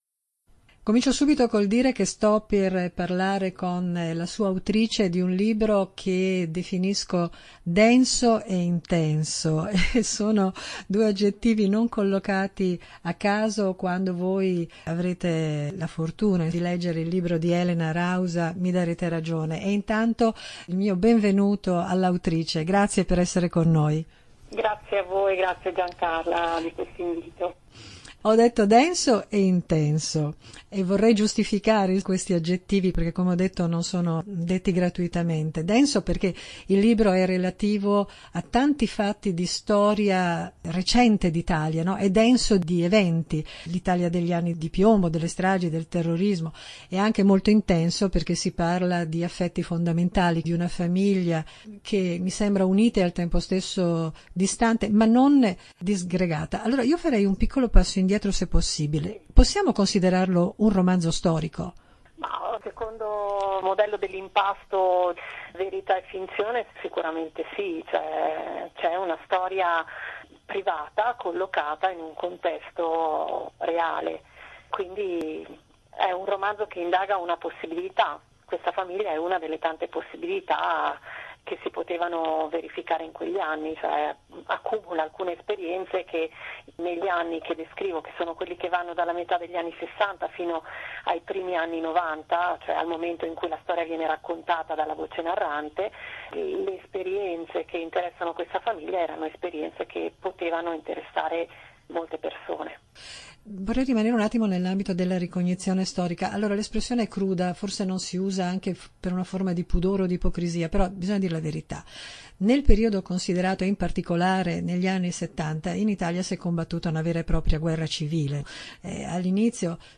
“Ognuno riconosce i suoi”: chiacchierata